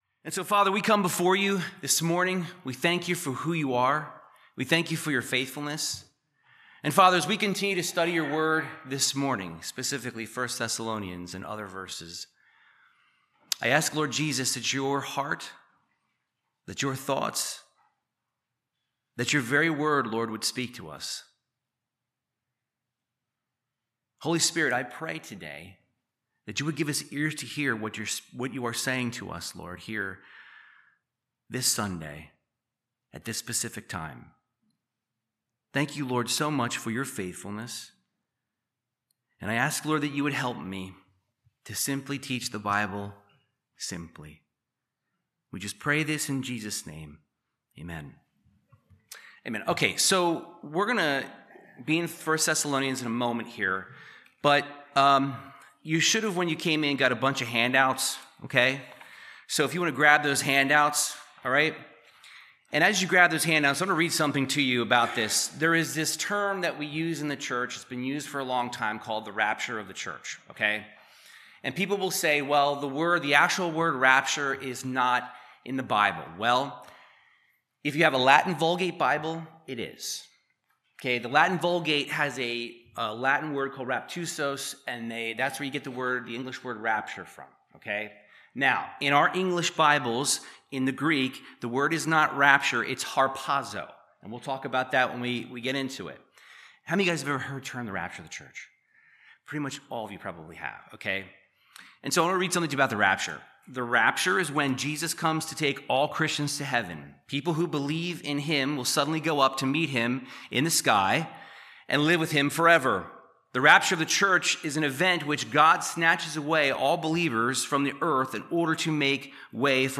Bible Teaching on The End Times. Part 5 discusses the Rapture and the hope and motivation it brings believers.